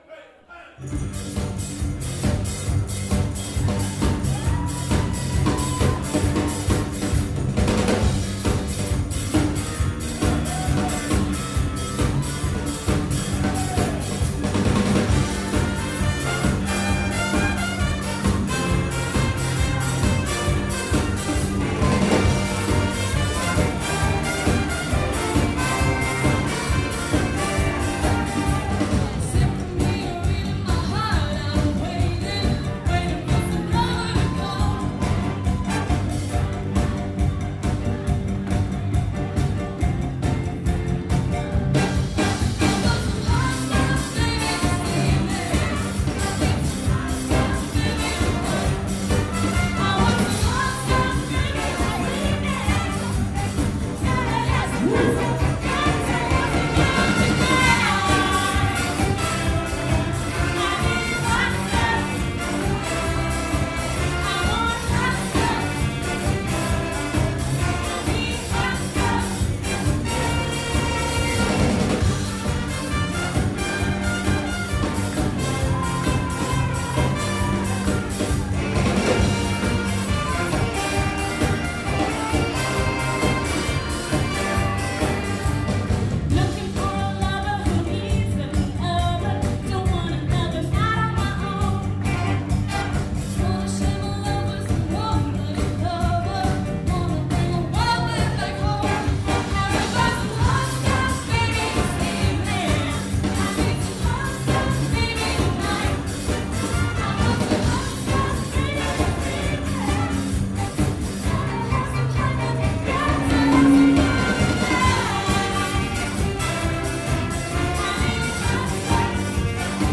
From the Big Band Evening March 2017